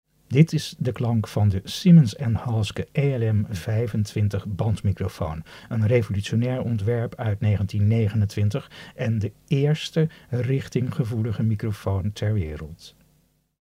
Luister naar het geluid van de Siemens & Halske ELM 25